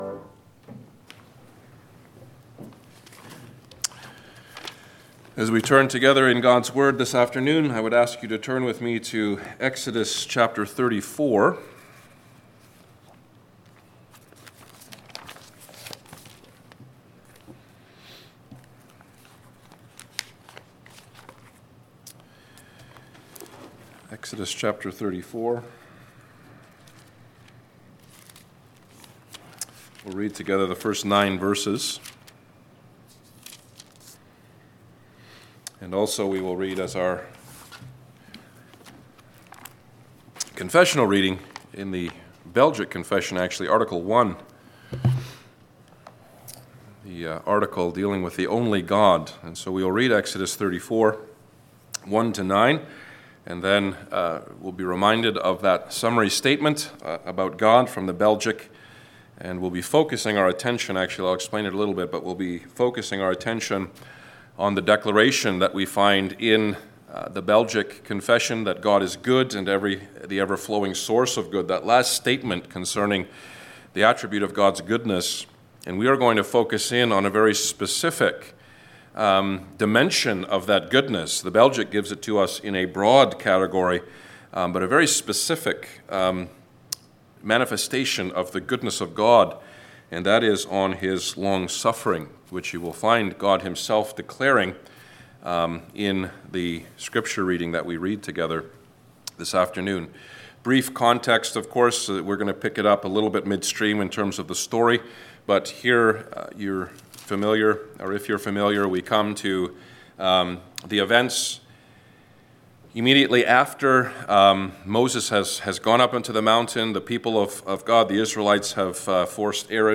Passage: Exodus 34:1-9 Service Type: Sunday Afternoon